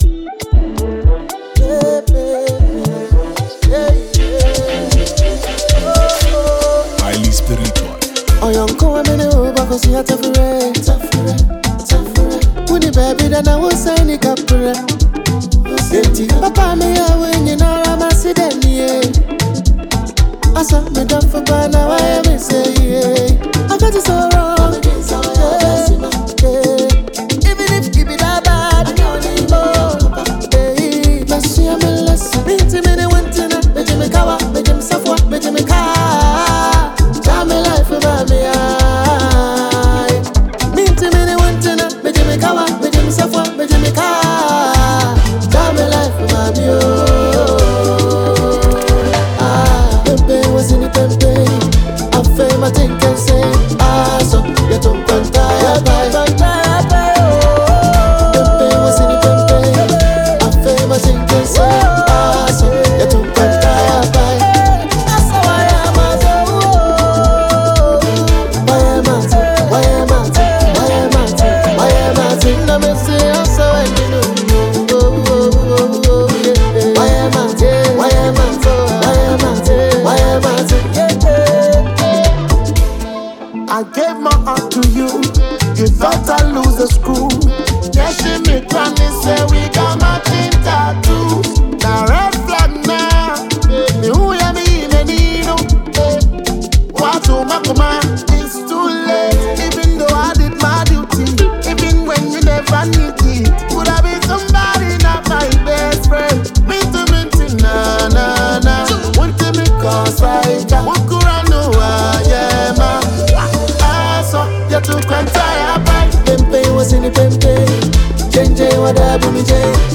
Genre: Highlife / Afro-Fusion